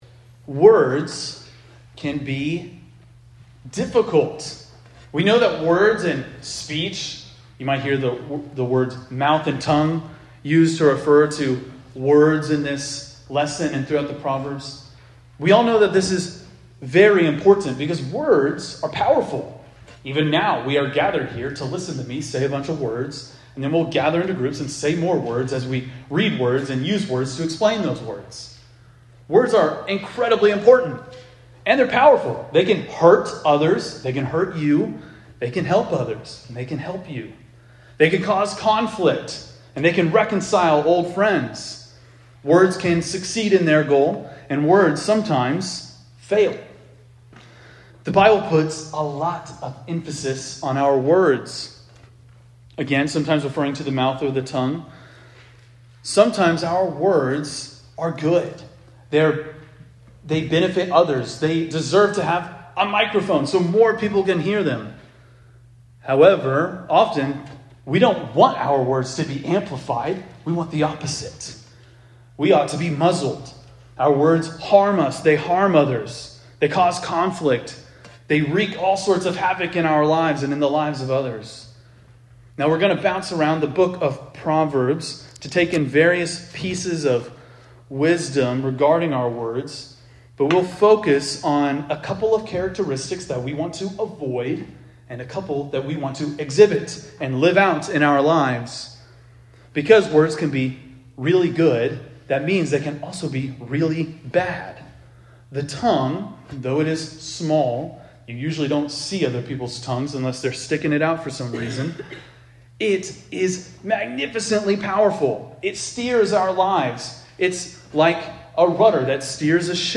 preaches on what the book of Proverbs says about our words.